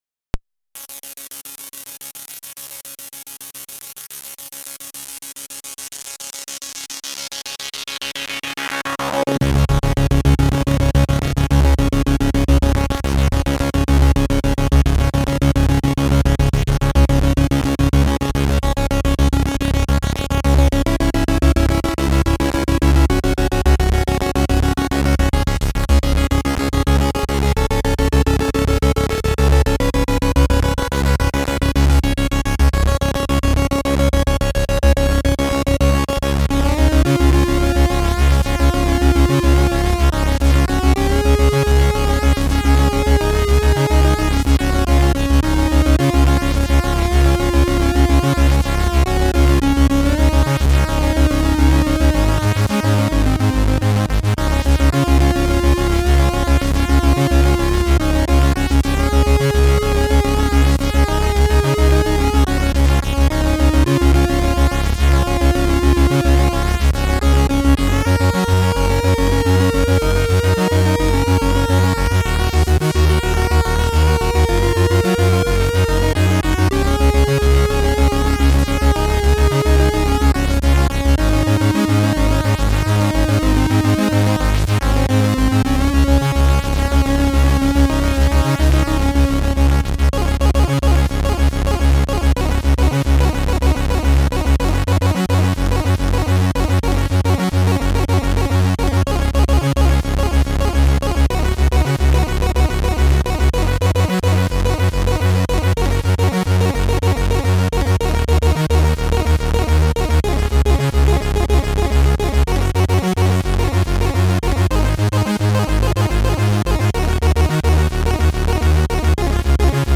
SID Version: 8580 (PAL)